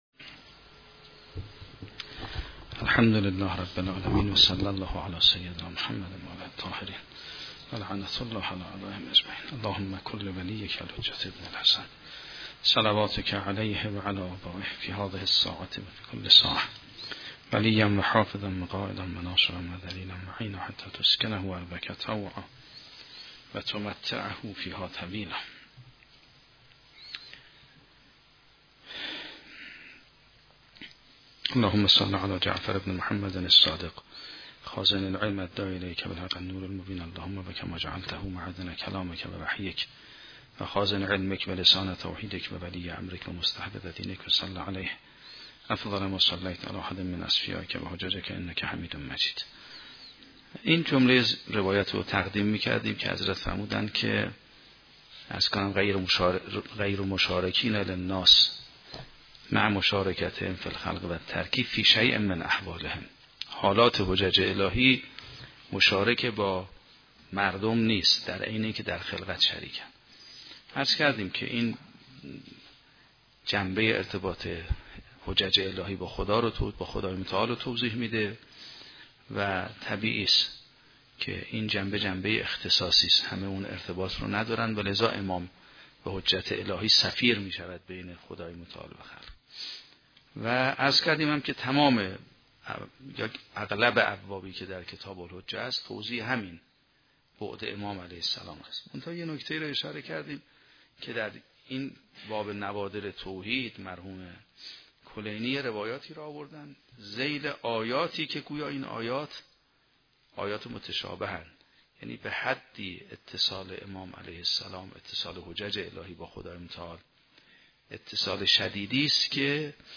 شرح و بررسی کتاب الحجه کافی توسط آیت الله سید محمدمهدی میرباقری به همراه متن سخنرانی ؛ این بخش : تبیین حقیقت اتصال و ارتباط با خدا از طریق اسماء الحسنای الهی